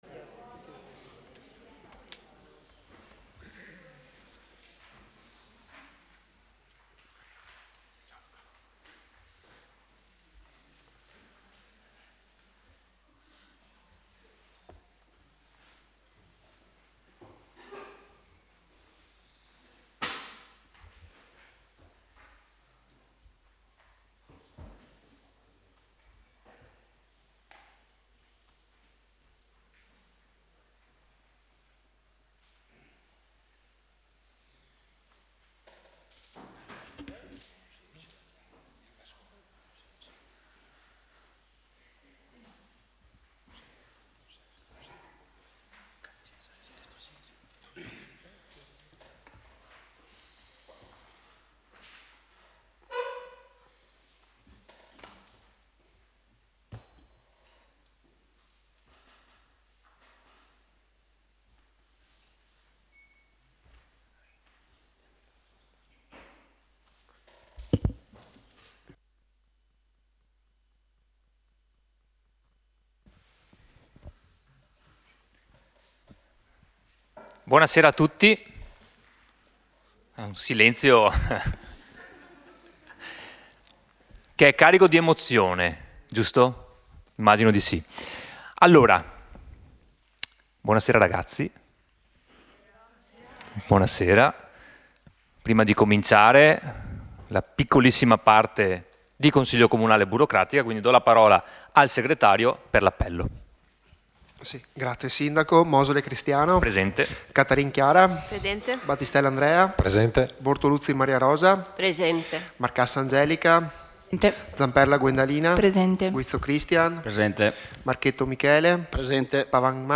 Sedute del Consiglio Comunale
Consiglio Comunale del 25 settembre 2025_audio